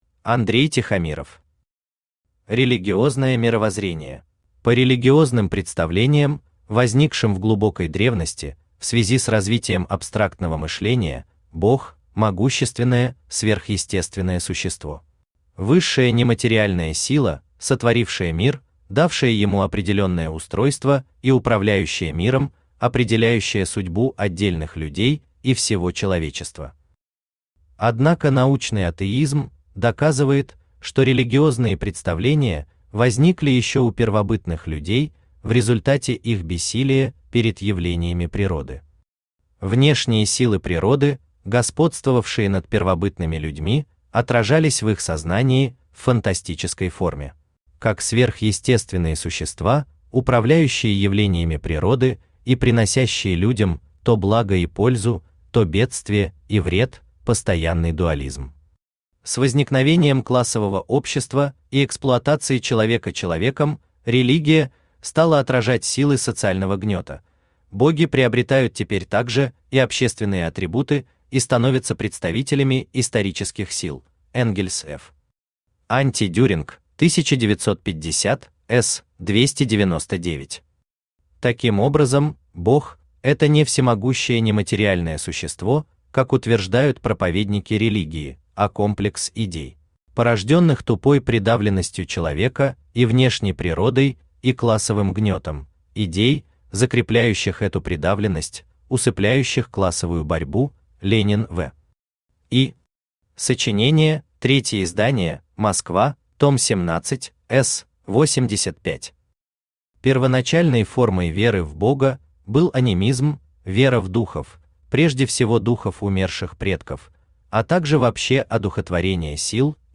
Аудиокнига Религиозное мировоззрение | Библиотека аудиокниг
Aудиокнига Религиозное мировоззрение Автор Андрей Тихомиров Читает аудиокнигу Авточтец ЛитРес.